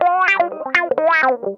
ITCH LICK 2.wav